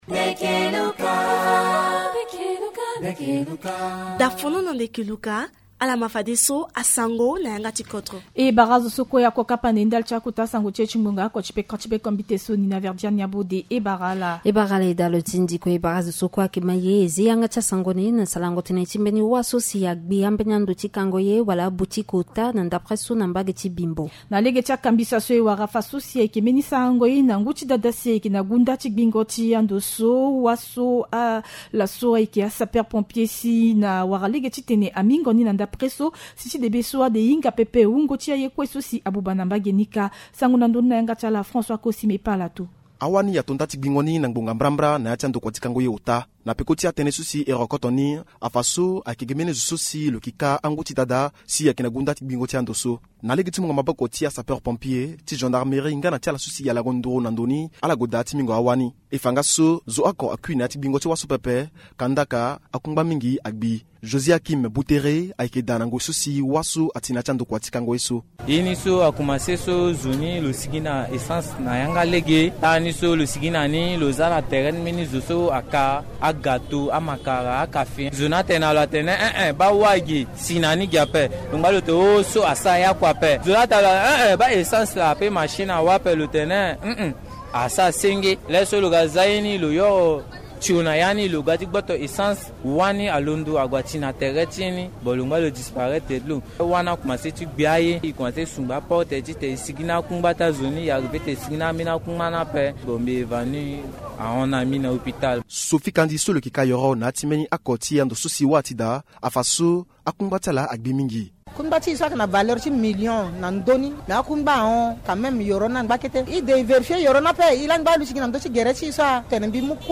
Journal en sango